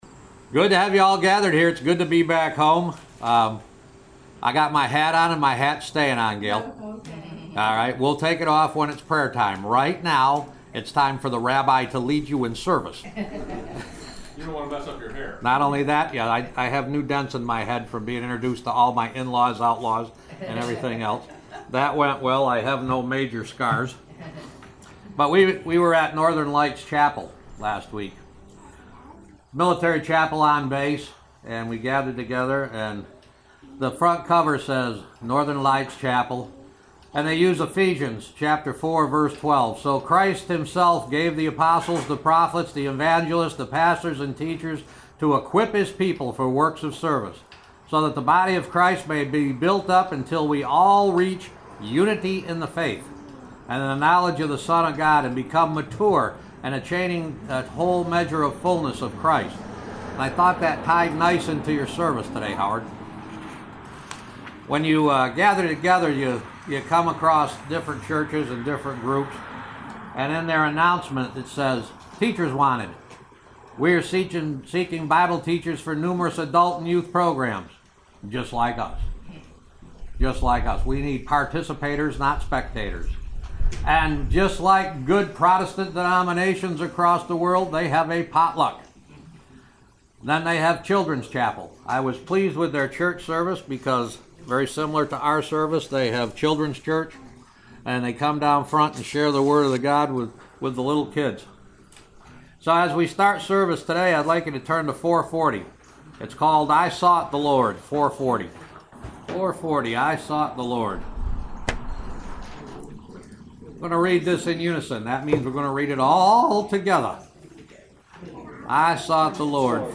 August 21st, 2016 Service Podcast
Hymn of Parting: #445 Just As I Am Benediction & Choral Amen